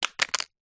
Звук упавшего на асфальт сотового телефона